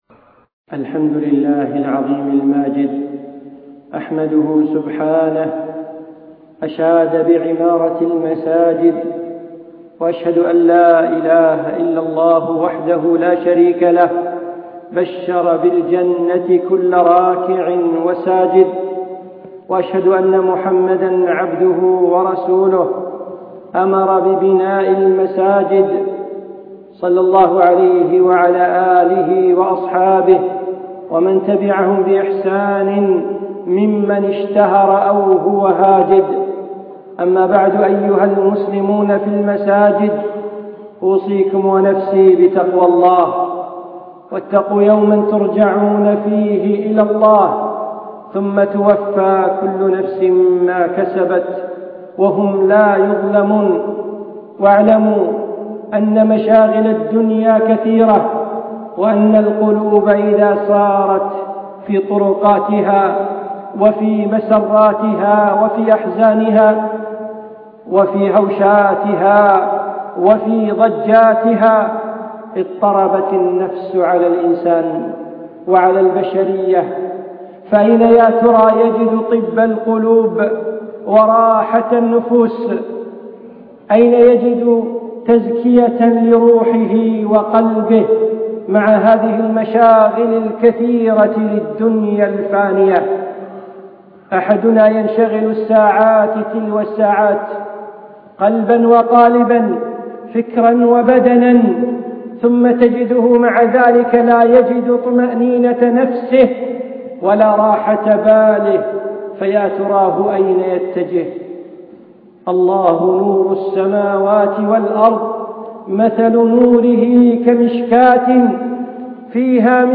خطبة للشيخ في دولة الإمارات